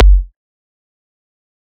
EDM Kick 43.wav